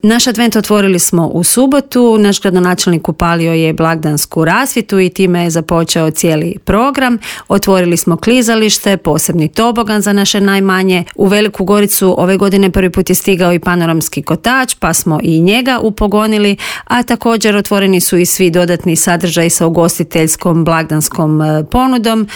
Advent u Gorici najavila je u Intervjuu Media servisa